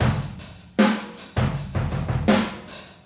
• 79 Bpm 1970s Drum Groove B Key.wav
Free drum loop sample - kick tuned to the B note. Loudest frequency: 1428Hz